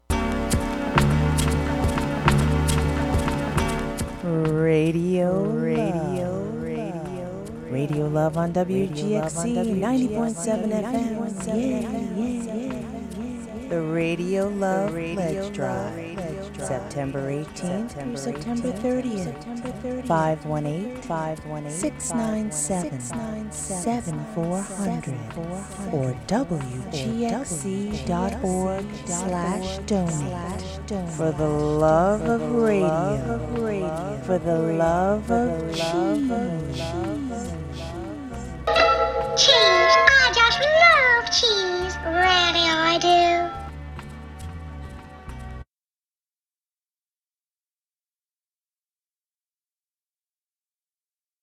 WGXC Hudson Studio (2011 - March 2018)